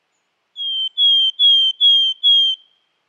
Art: Granmeis (Poecile montanus)
Sang
Lyder: Granmeisen har en karakteristisk nasal og grov «tææh tææh»-lyd, men også tynne «ti-ti» – eller «siu-siu-siu-siu»-lyder.